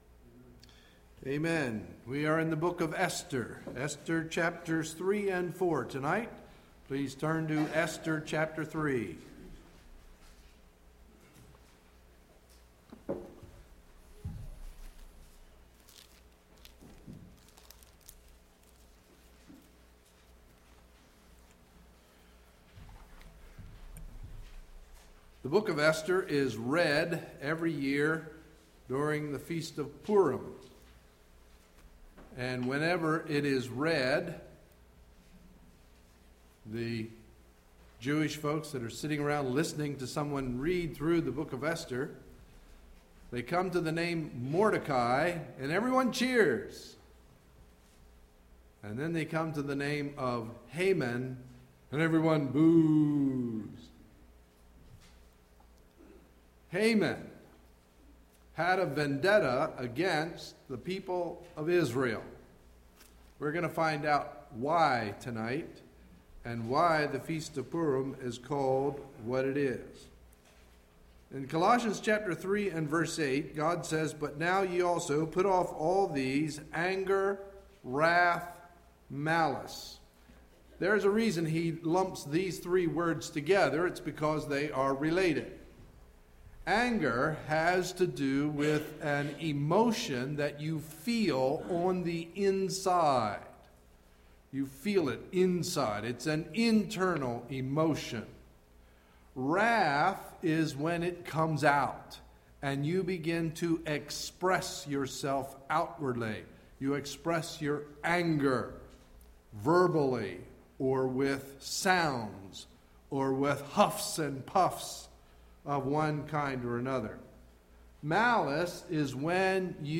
Sunday, April 3, 2011 – Evening Message